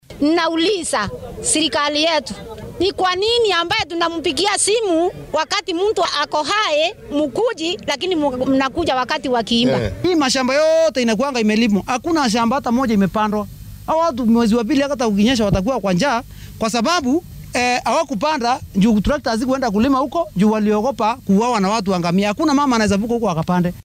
Shacabka-Meru.mp3